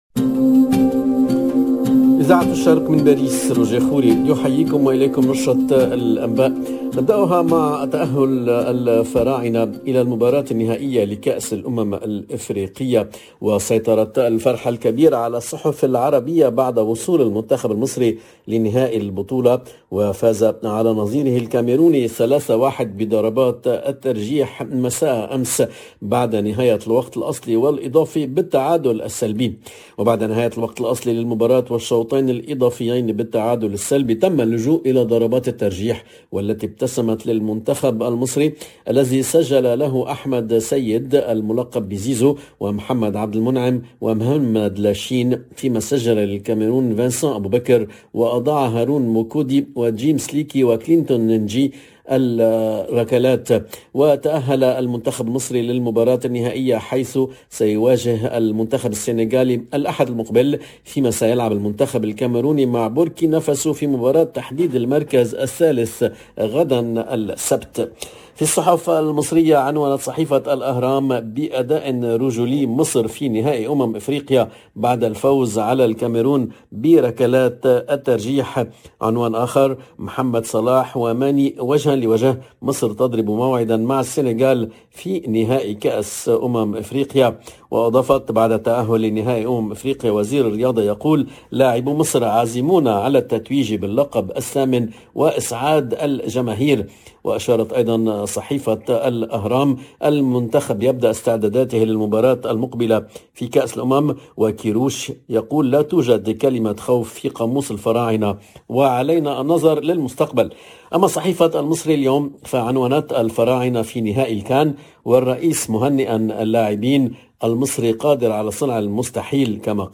LE JOURNAL EN LANGUE ARABE DE LA MI-JOURNEE DU 4/02/22